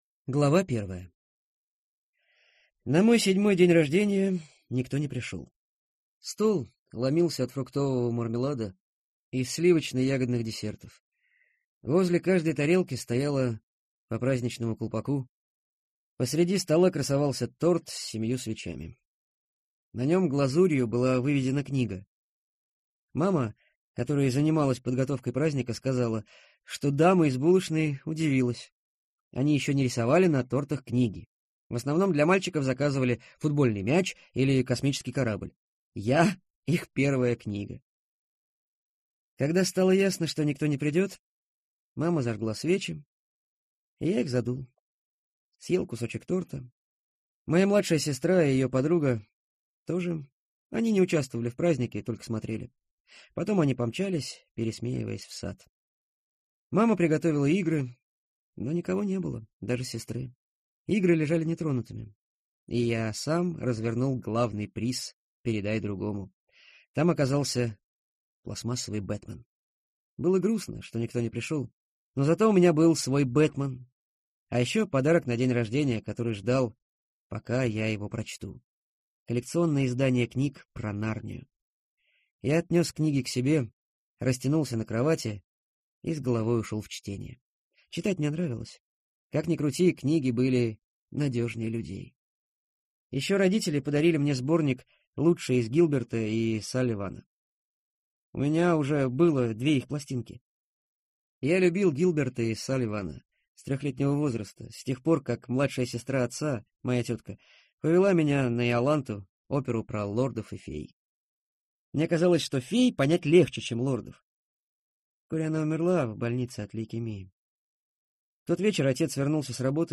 Аудиокнига Океан в конце дороги | Библиотека аудиокниг